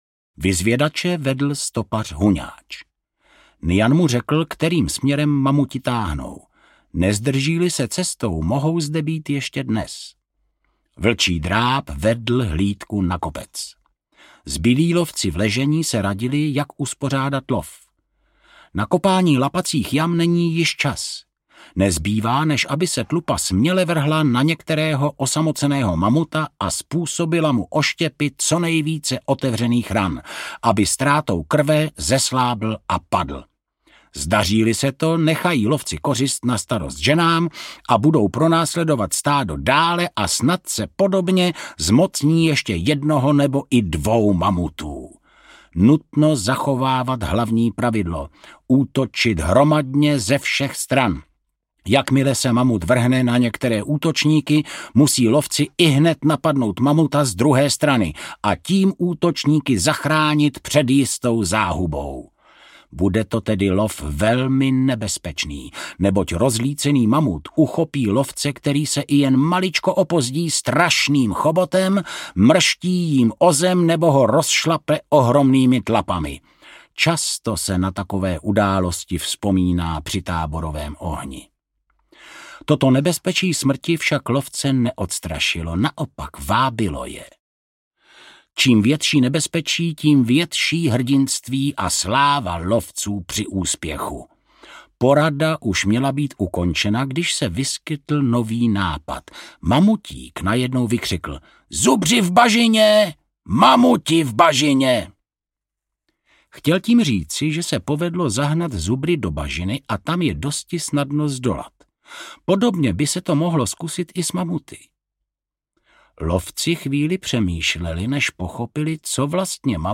Lovci mamutů audiokniha
Ukázka z knihy
| Vyrobilo studio Soundguru.